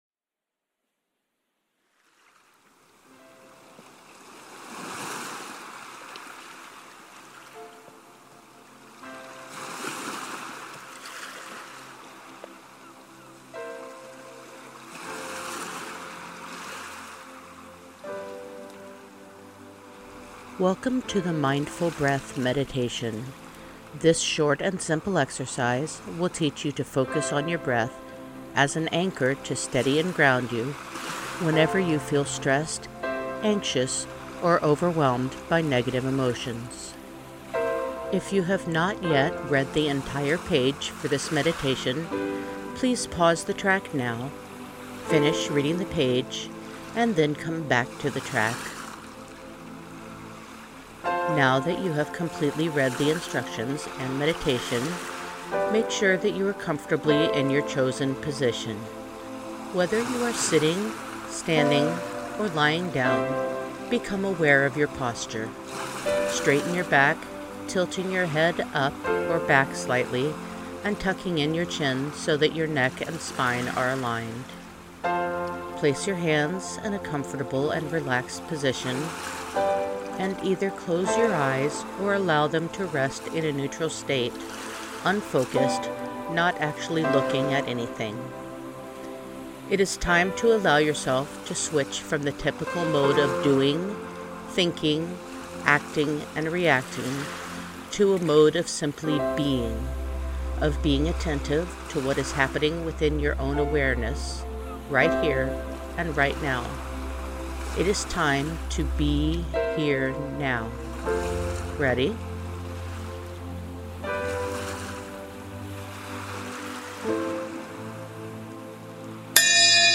The guided meditation track uses a chime to begin this meditation, as do most of the meditations in this series.
GuidedMeditation-mindfulbreath.mp3